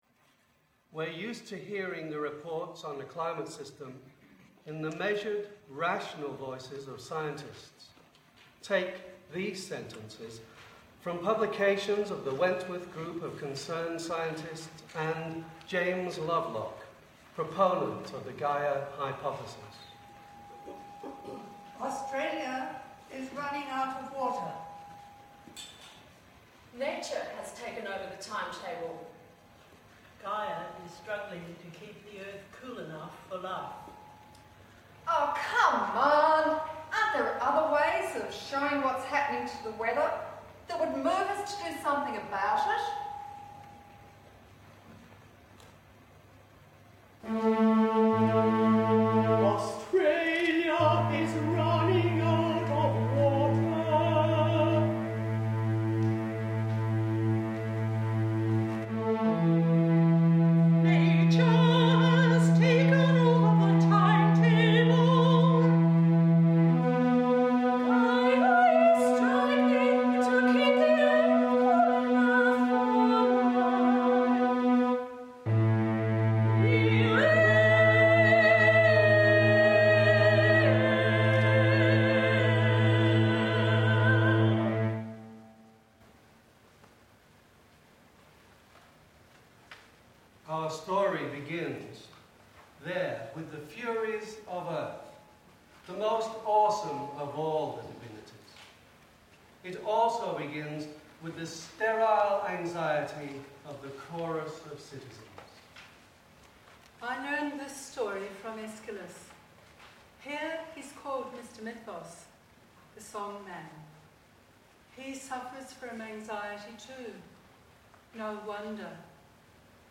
Violent Ends: The Arts of Environmental Anxiety 01 Jan 2018 Reconciliation Hear ‘Reconciliation’ performed by A Chorus of Women and Wayfarers Australia. Open player in a new tab Presenters: Chorus of Women and Wayfarers Australia Tags: arts environment 00:00 / 25:58 Download Reconciliation audio file (11.9 MB)